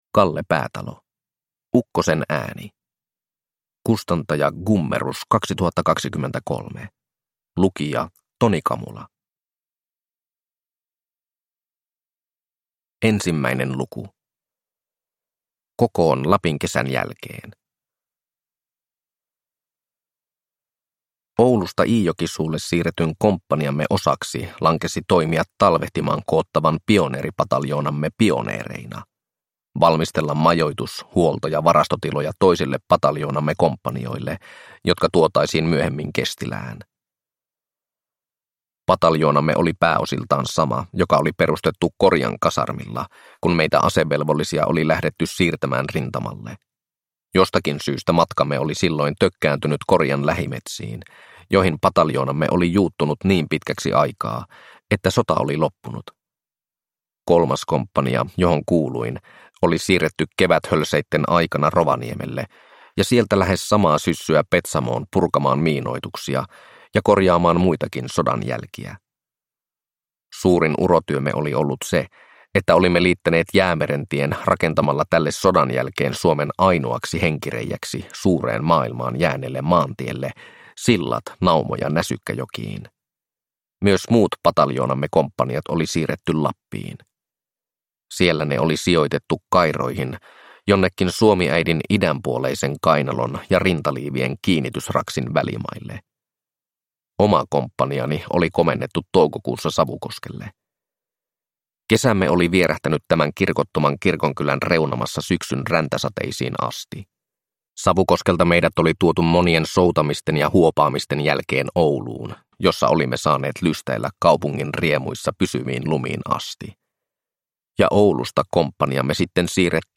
Ukkosen ääni – Ljudbok – Laddas ner